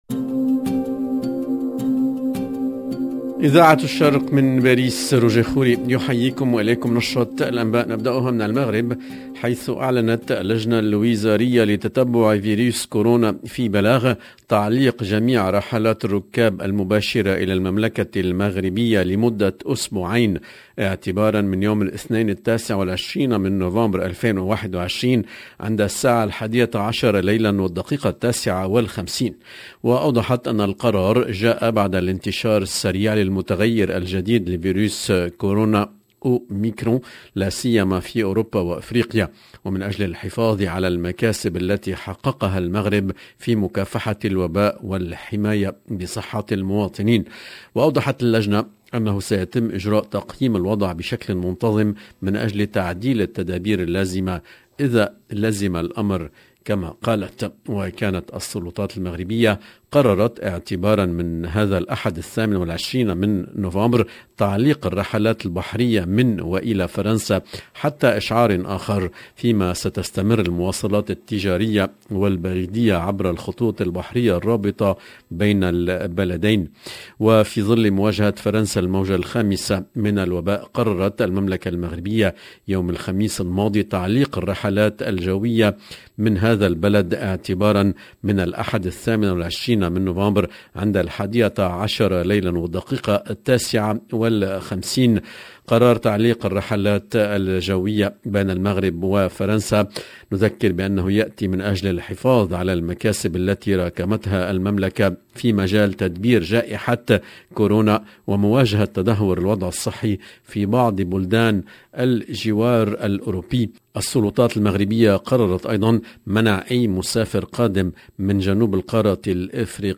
EDITION DU JOURNAL DU SOIR EN LANGUE ARABE 28/11/2021